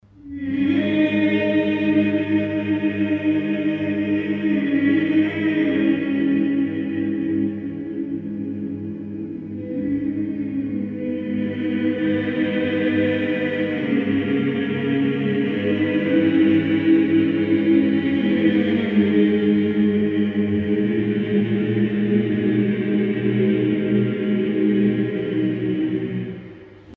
Chant
Pièce musicale éditée